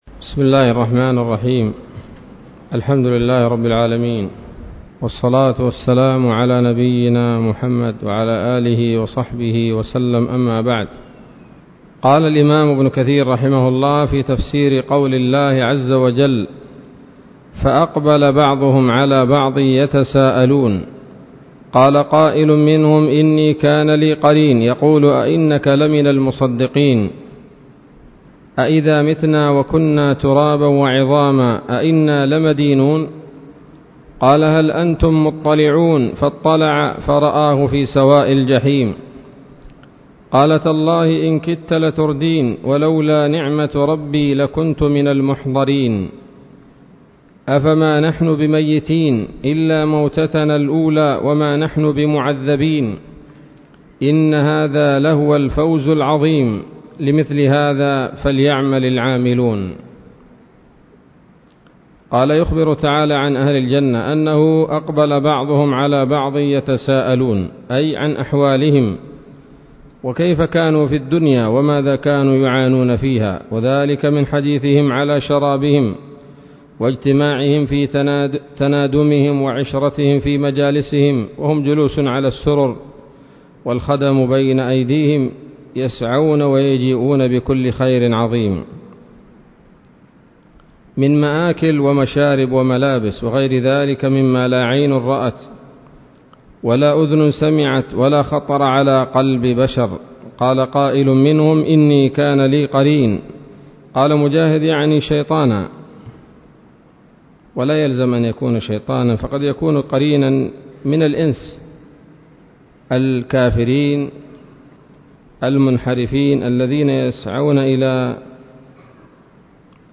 الدرس الخامس من سورة الصافات من تفسير ابن كثير رحمه الله تعالى